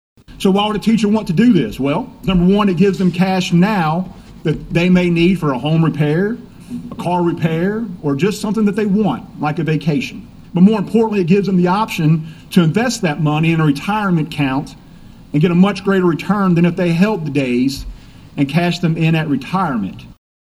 State Senator Matt Nunn is the sponsor of the bill, and addressed the issue on the Senate floor.(AUDIO)